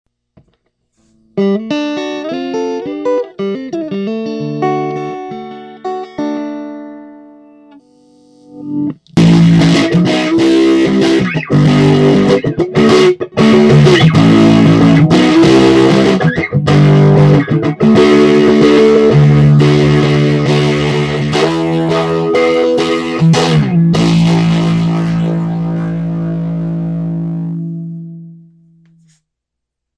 I used my PC headset mike direct into a Toshiba laptop.
I kept the amp maxed and played it through an LPAD attenuator set pretty low.
Cuts 1-3 are pure, raw cuts, and are a bit misleading. The recording system filters out lots of the true richness of this amp.
Starts cool, then crank the volume to flanging grind
cool2flange.mp3